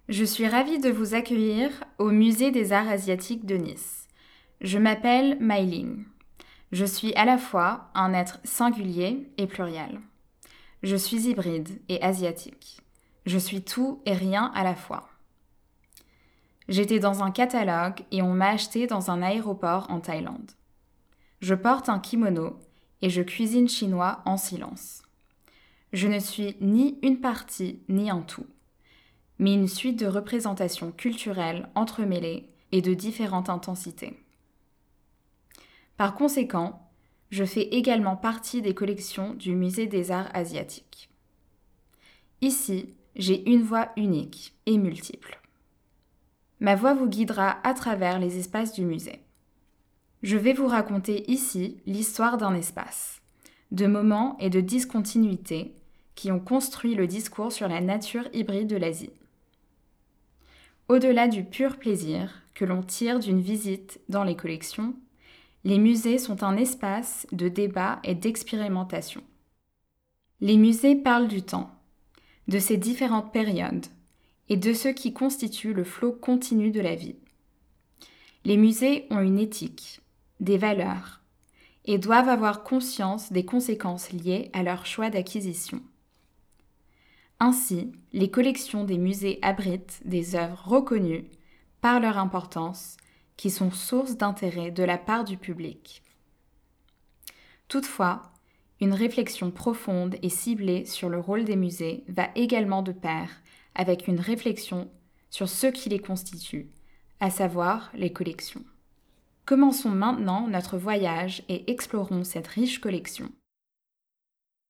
Audioguides  :